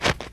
snow-08.ogg